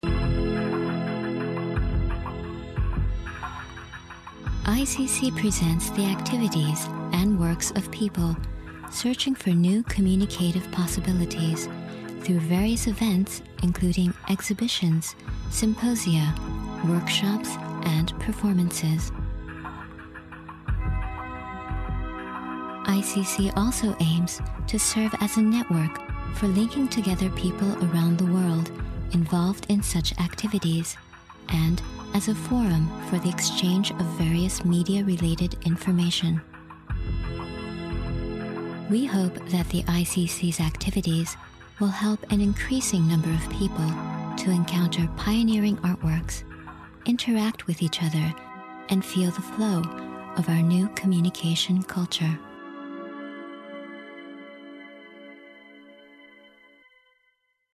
日本語 女性